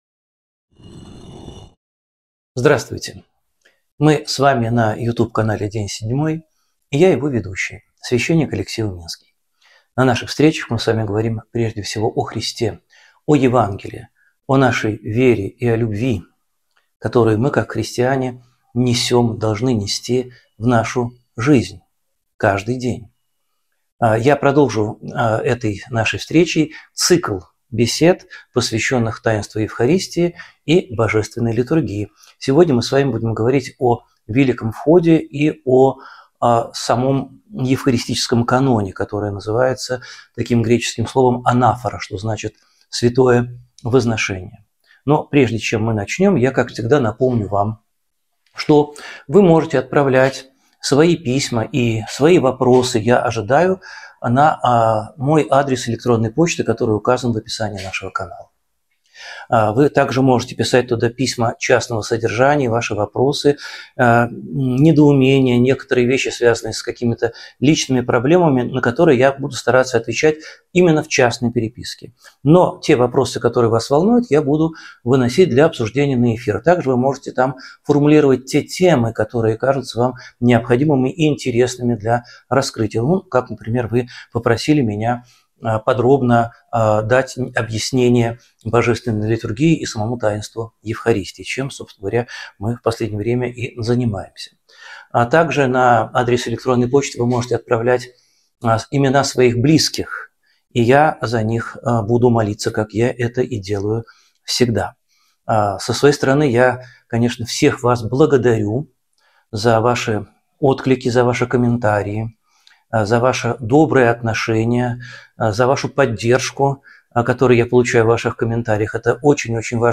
Эфир ведёт Алексей Уминский
Alexey Uminskiy Что происходит на службе во время Великого Входа, Херувимской песни и Евхаристии? Продолжение цикла бесед о богослужении со священником Алексеем Уминским (часть 4).